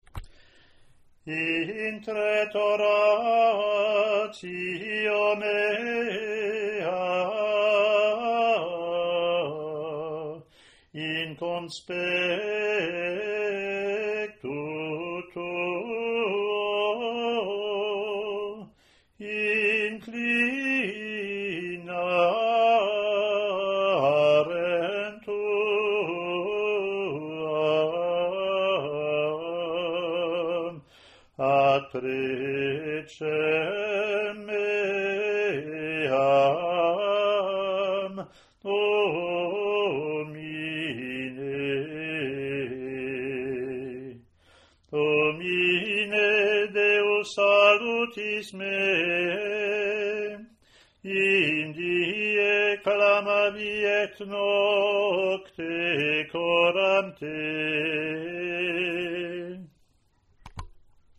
Latin antiphon and verse)